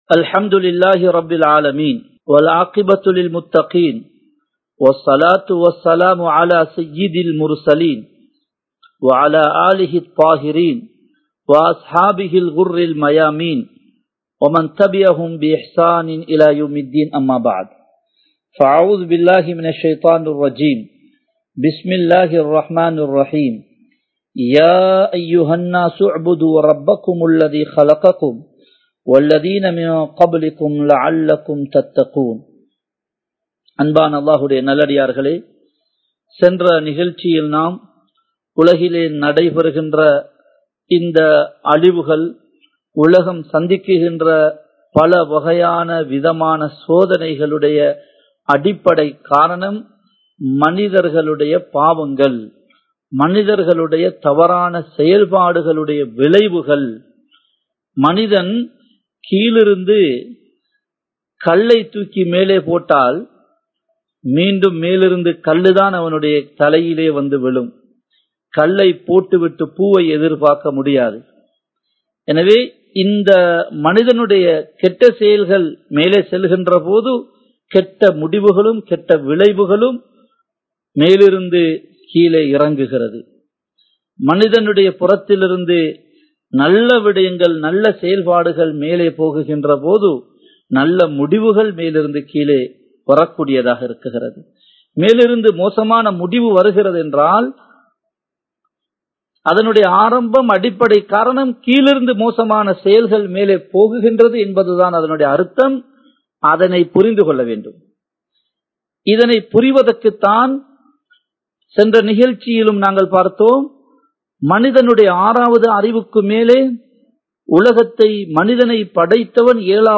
Soathanaihalukkana Theervuhal Evai? (சோதனைகளுக்கான தீர்வுகள் எவை?) (Part 02) | Audio Bayans | All Ceylon Muslim Youth Community | Addalaichenai
Live Stream